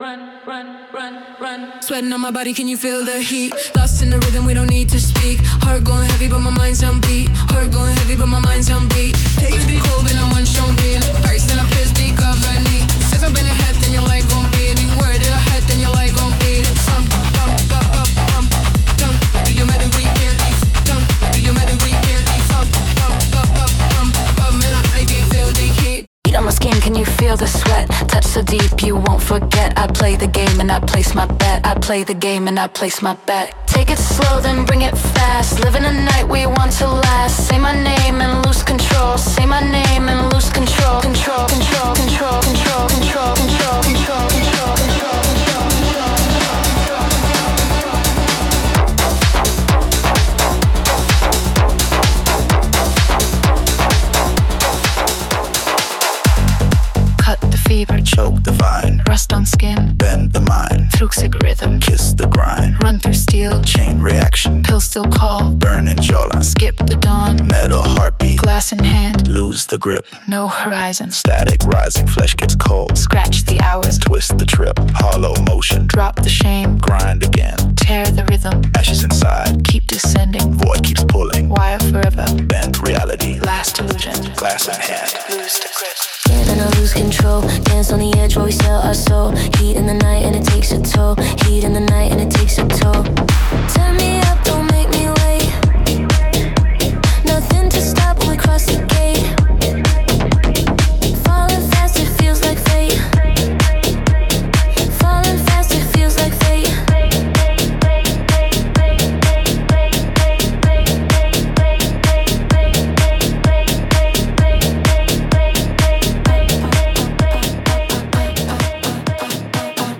デモサウンドはコチラ↓
Genre:Tech House
77 Male Vocal 128 Bpm
74 Female Vocal 128 Bpm